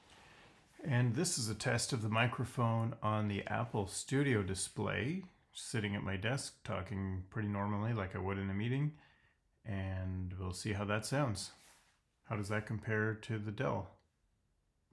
For those wondering about the microphone quality, I compared the U3224KB, Studio Display, and my RE20 dynamic microphone.
The Dell sounds a bit tinny and less clear than the ASD's mic, but acceptable and intelligible.